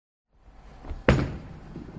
Free SFX sound effect: Sword Clash.
Sword Clash
558_sword_clash.mp3